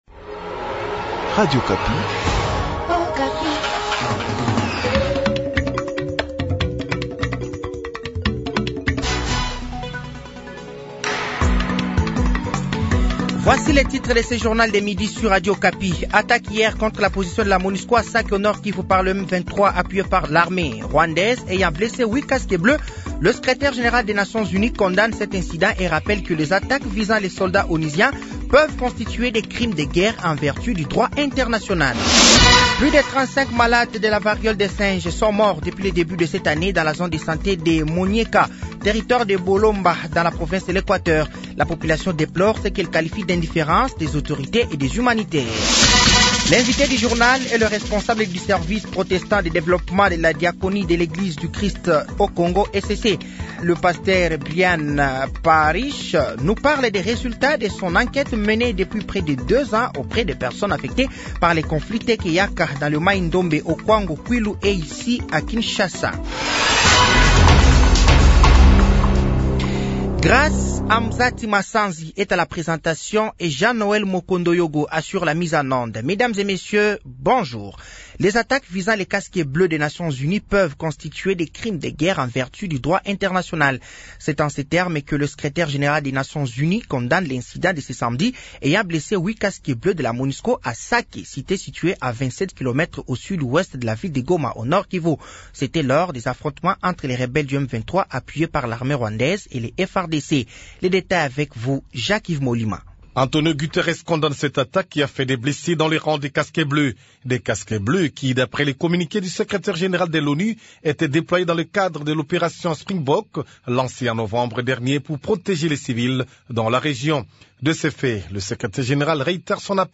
Journal français de 12h de ce dimanche 17 mars 2024